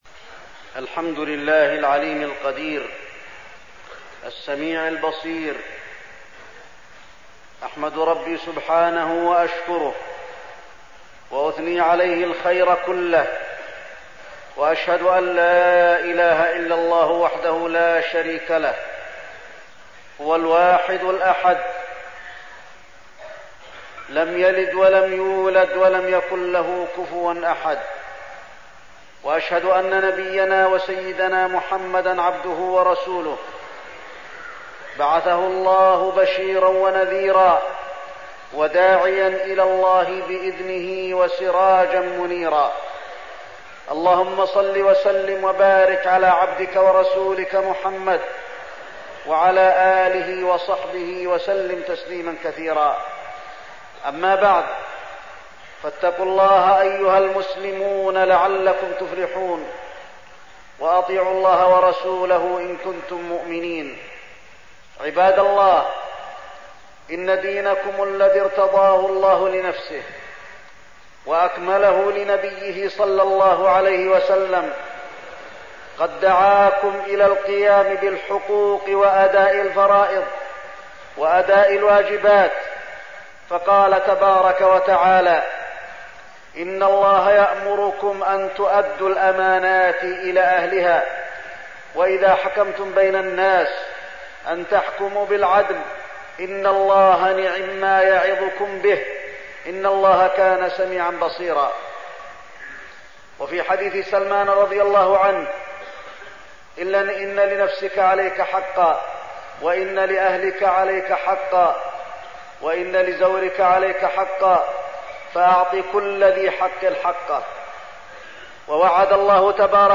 تاريخ النشر ١٢ ذو القعدة ١٤١٧ هـ المكان: المسجد النبوي الشيخ: فضيلة الشيخ د. علي بن عبدالرحمن الحذيفي فضيلة الشيخ د. علي بن عبدالرحمن الحذيفي الأخوة في الإسلام